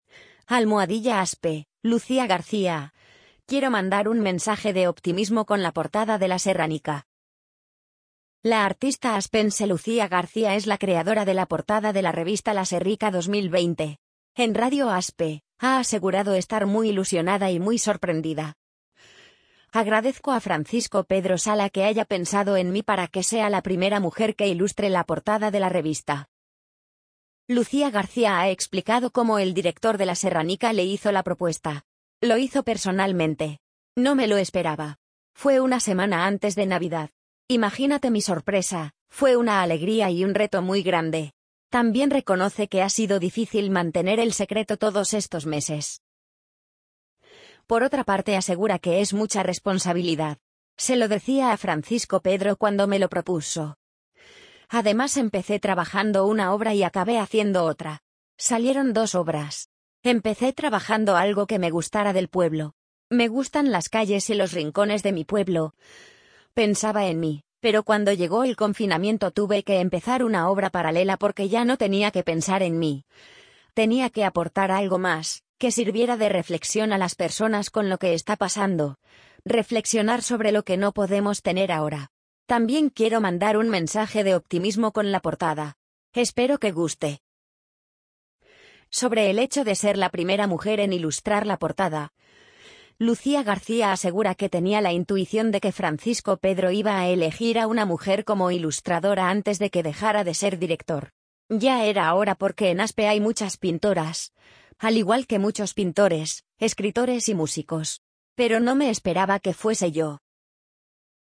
amazon_polly_44067.mp3